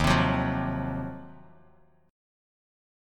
D#m6add9 chord